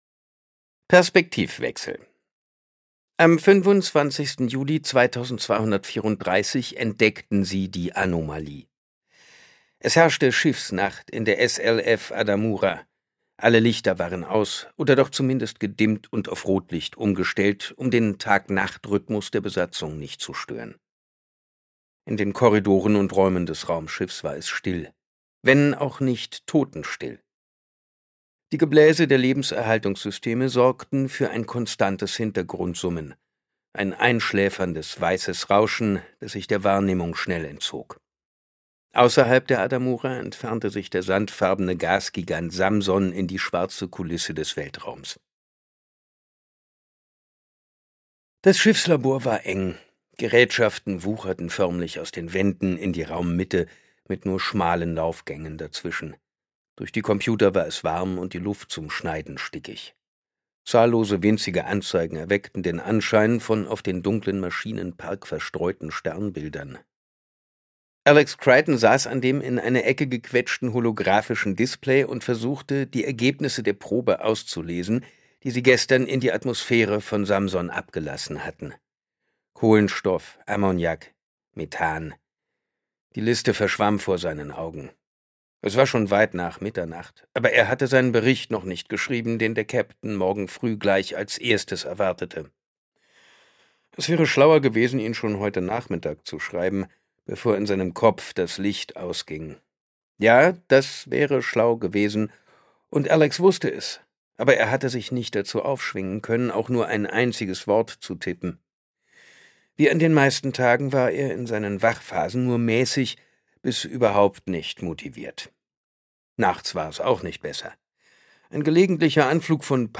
Fractal Noise Mission ins Ungewisse | Packende SciFi vom Bestsellerautor von »Eragon« Christopher Paolini (Autor) Simon Jäger (Sprecher) Audio Disc 2024 | 1.